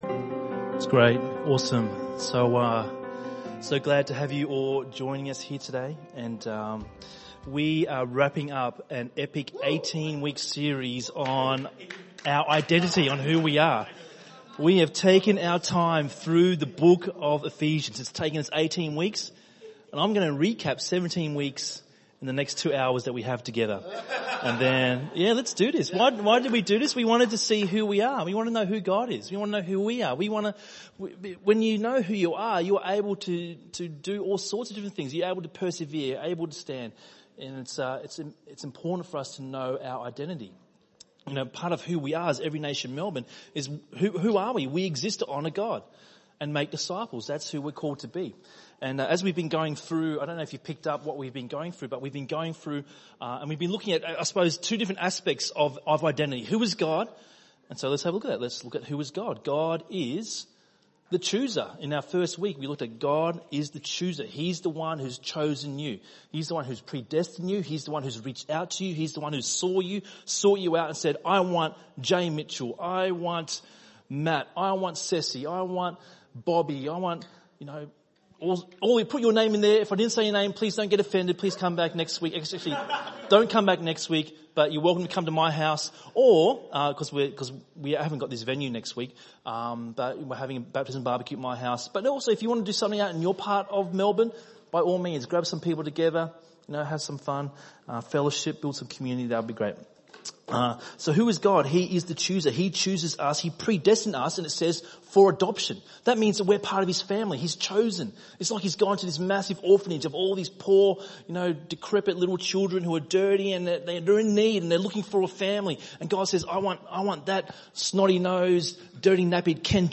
ENM Sermon